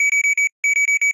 pda_alarm.ogg